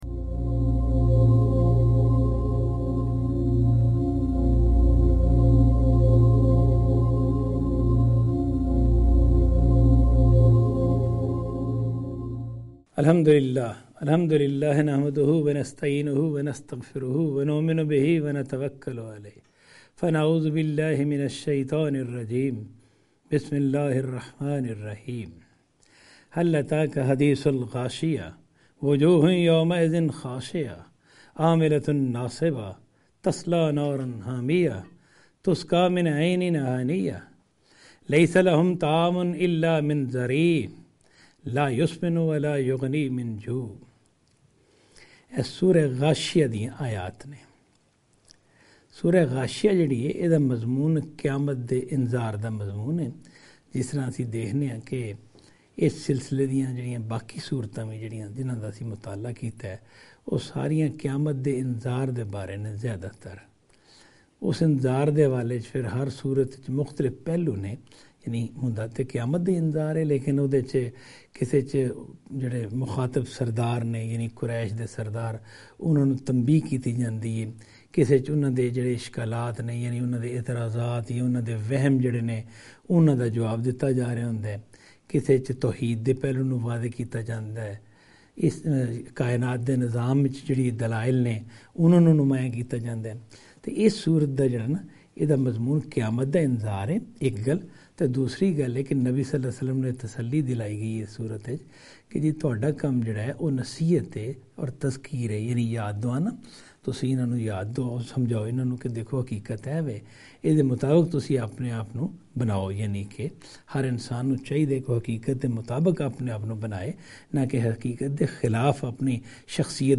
Qur'anic Surah Lecture series in Punjabi